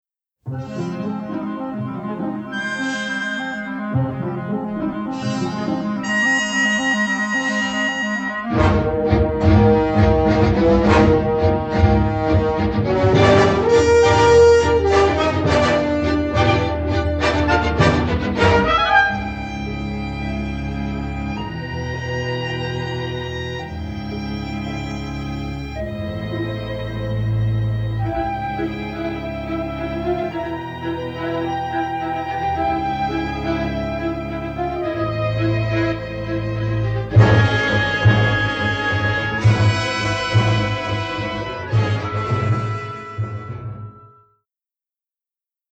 western score
complete score mastered in mono from print takes